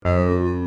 Sequencial Circuits - Prophet 600 34